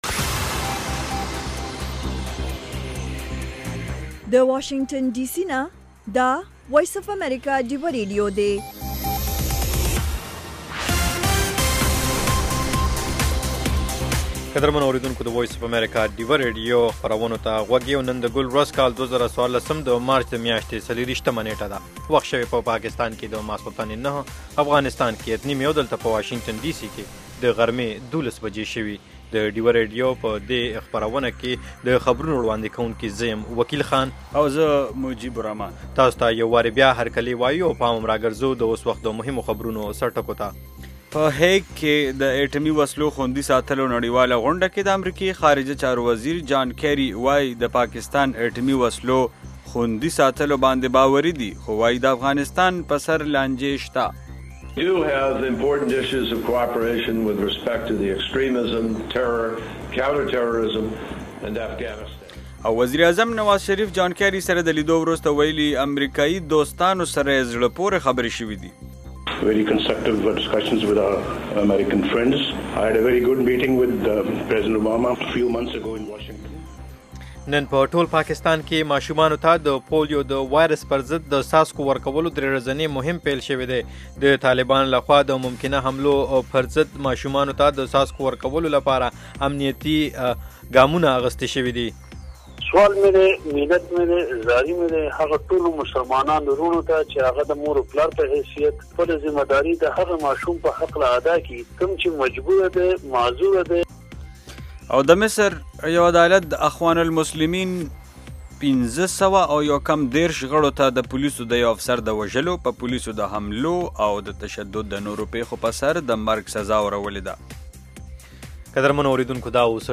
دا یو ساعته خپرونه خونده ورې سندرې لري میلمانه یې اکثره سندرغاړي، لیکوالان، شاعران او هنرمندان وي.